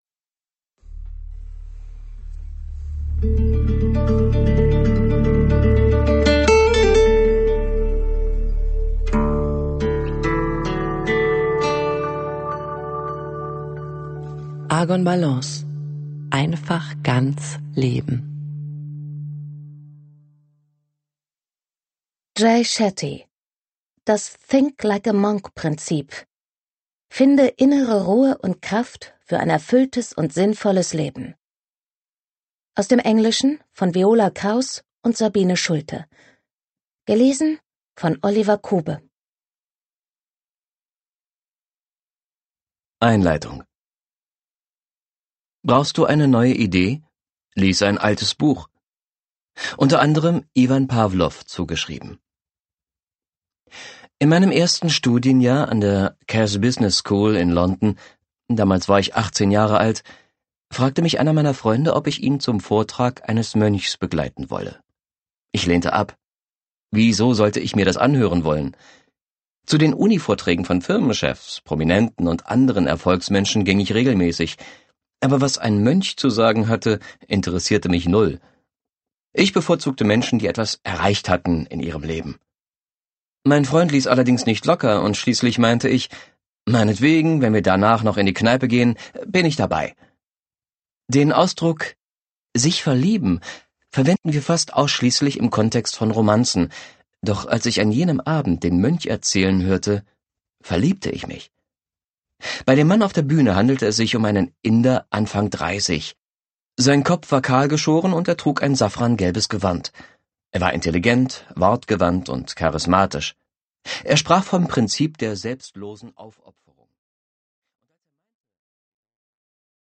digital digital digital stereo audio file Notes